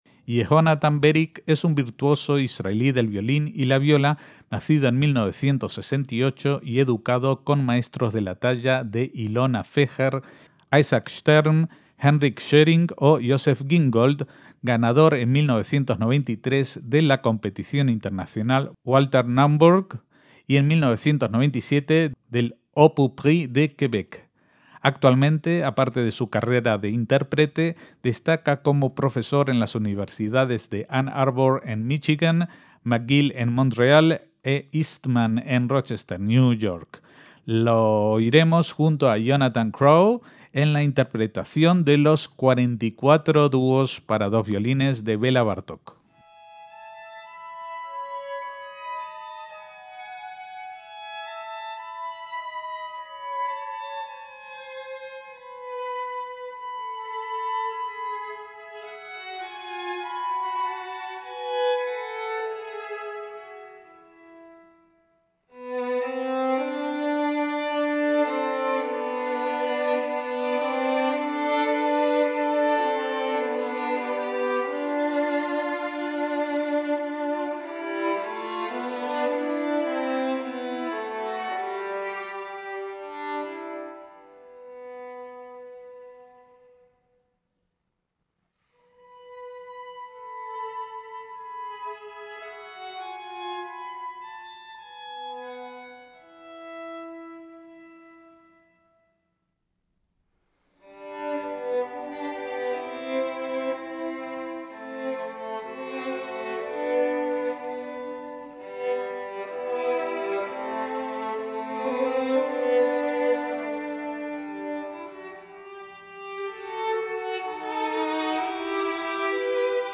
Dúos para violines
MÚSICA CLÁSICA - Cuando emitimos originalmente este programa el violinista y violista israelí tenía aún por delante una brillante carrera que se frustró a los 52 años de edad por un cáncer terminal.